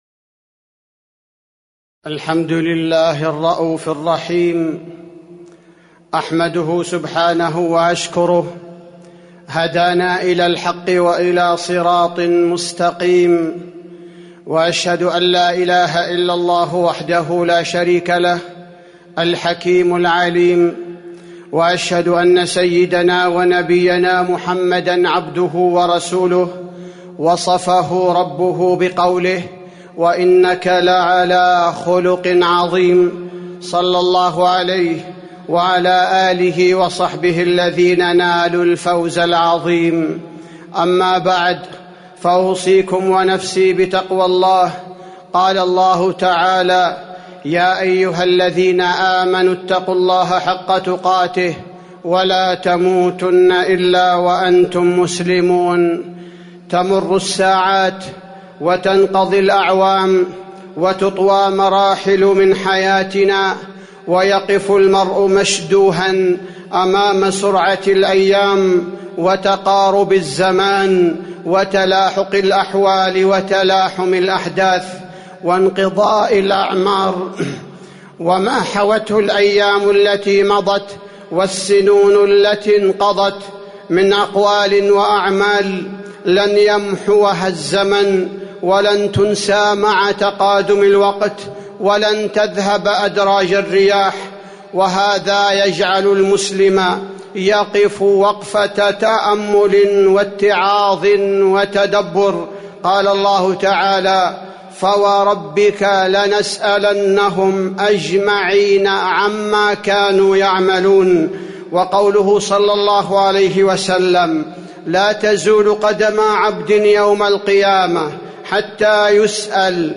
تاريخ النشر ٢٧ ذو الحجة ١٤٤٢ هـ المكان: المسجد النبوي الشيخ: فضيلة الشيخ عبدالباري الثبيتي فضيلة الشيخ عبدالباري الثبيتي صحيفة الأعمال